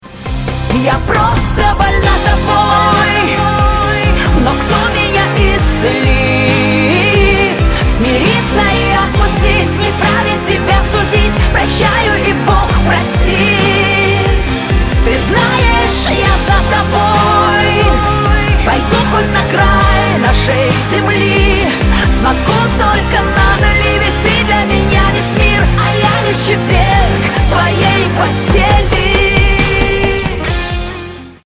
• Качество: 256, Stereo
поп
женский вокал